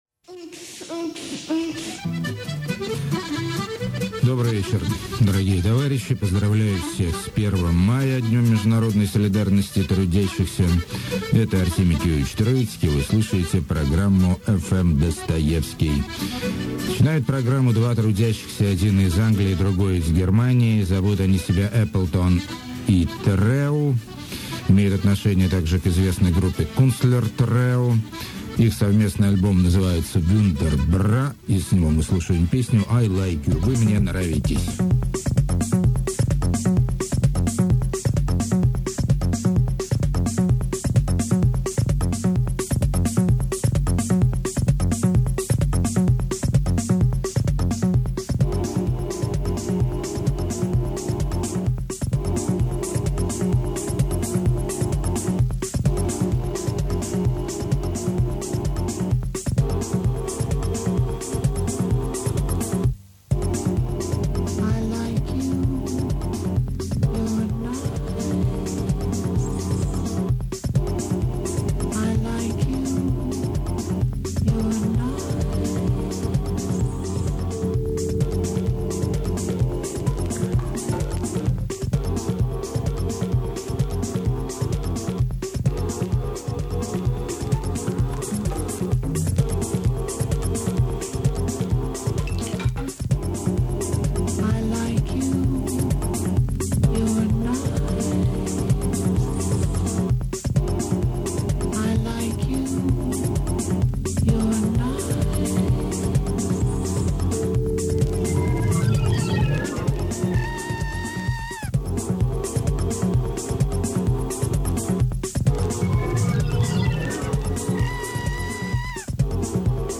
elegant eronic electronic
children fun songs
dark lo-fi songwriting
sexy bilingual electro
100 years old brass band
perfect 60s garage pop
violin-flavored downtempo
consumerist kitsch pop
poetic post-rock